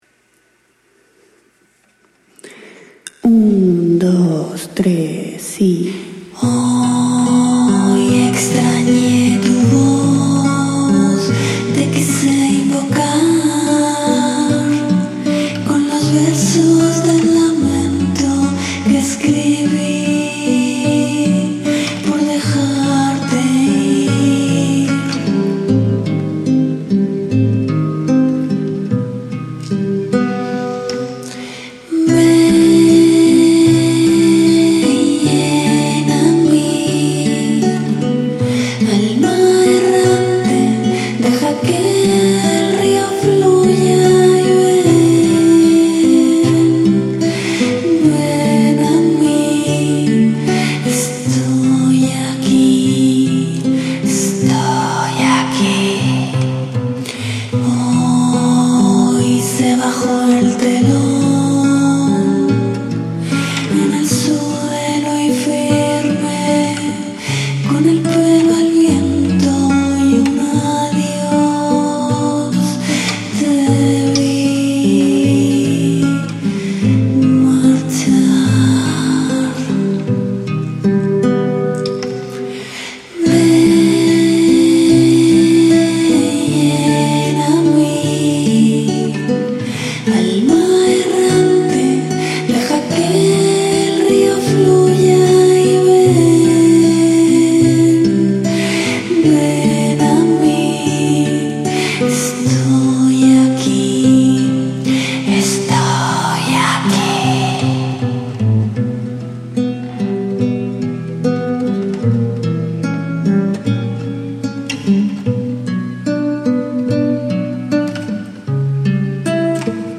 MAQUETA
Alma-Errante-Acustico.mp3